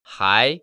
[hái]
하이